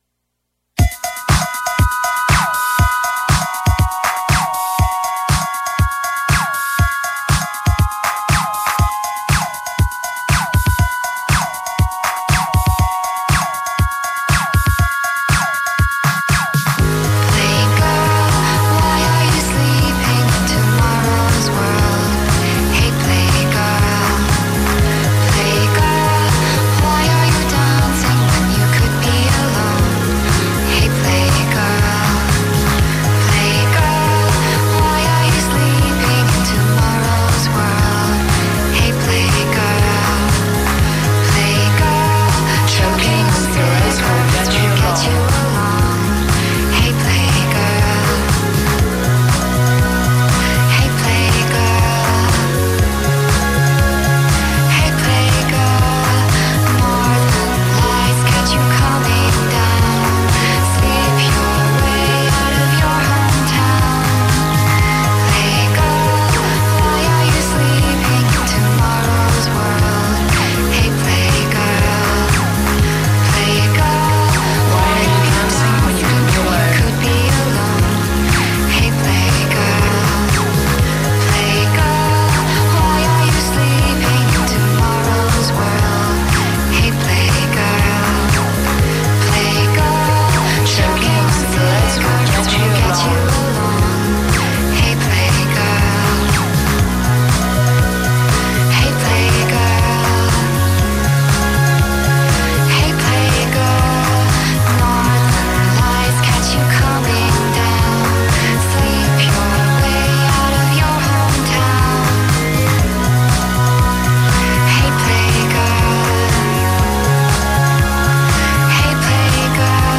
(Please note that these shows may contain explicit lyrics.)